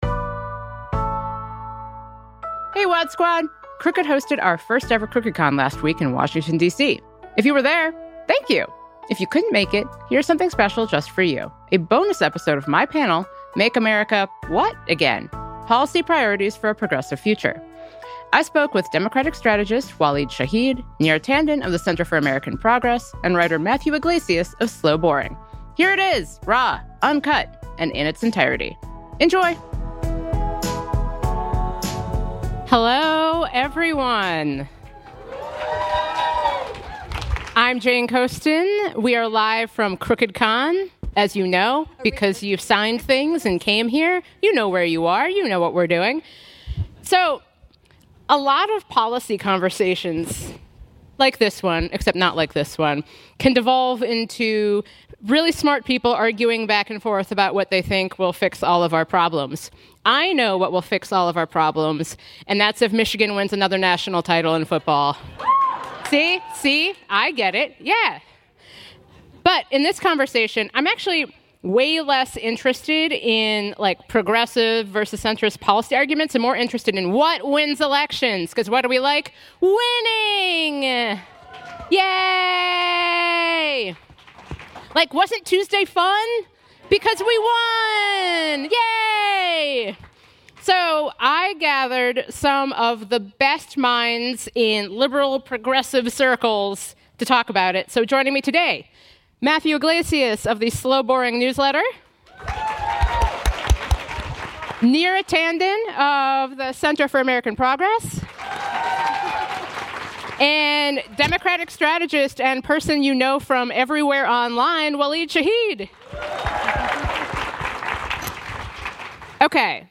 Jane Coaston talks with three of the left’s most prominent policy thinkers: